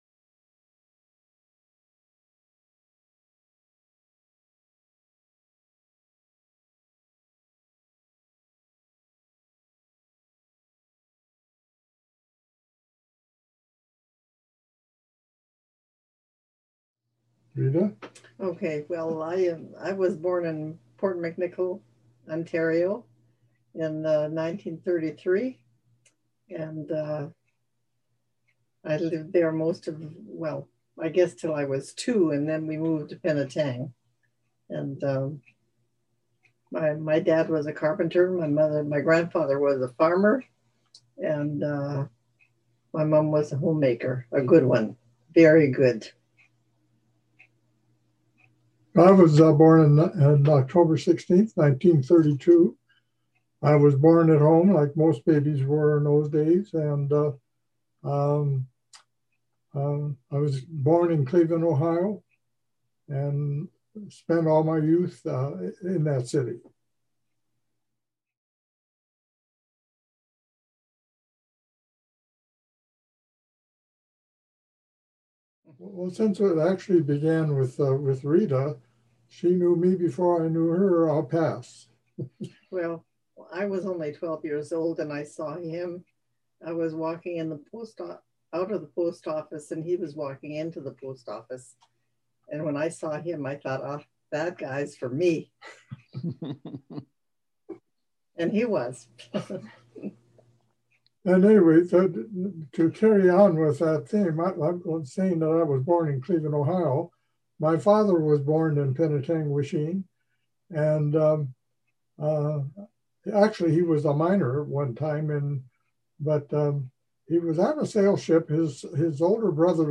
We are so glad to have you with us for our Sunday Service.